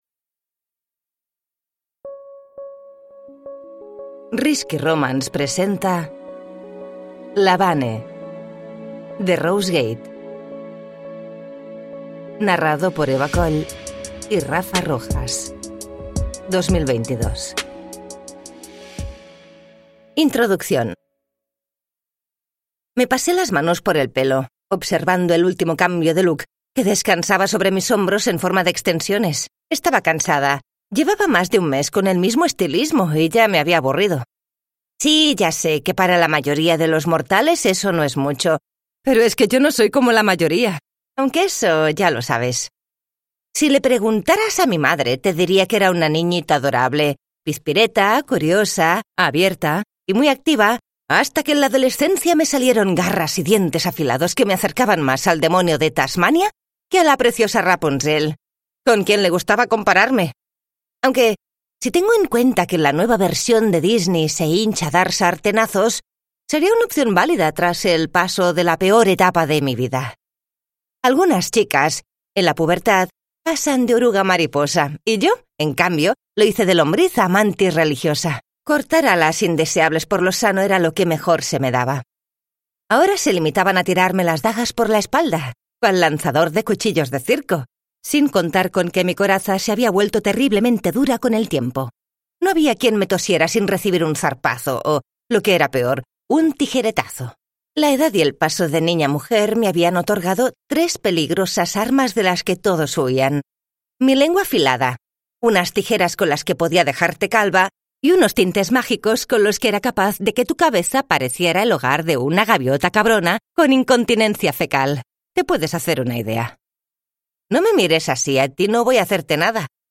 La Vane: Soy sexy de nacimiento y cabrona por entretenimiento (ljudbok) av Rose Gate